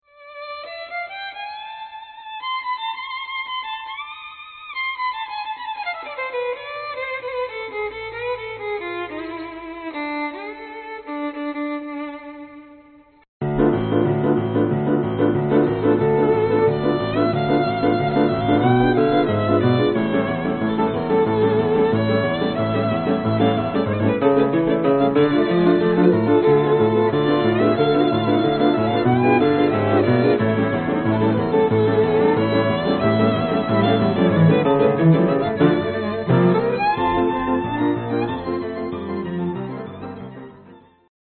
virtuoso violinist
established by excellent cellist
virtuoso pianist
- eastern european
- colourful world folk